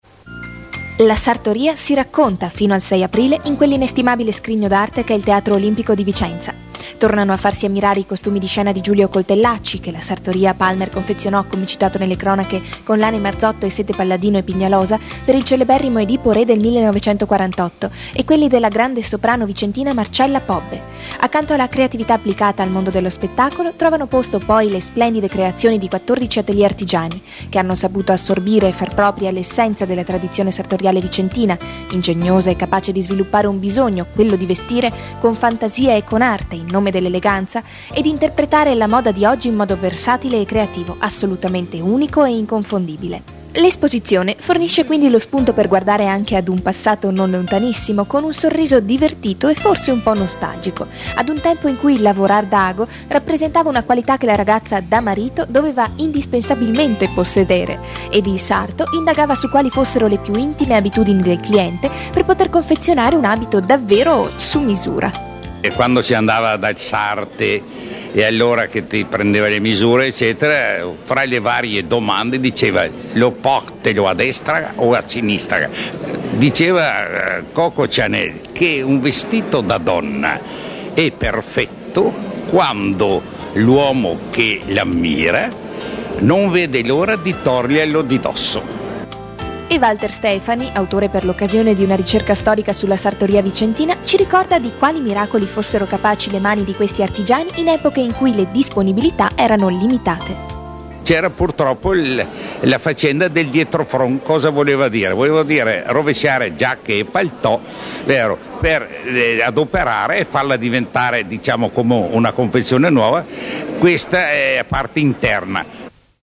all’inaugurazione della mostra “La sartoria si racconta”, organizzata al Teatro Olimpico di Vicenza (marzo 2003).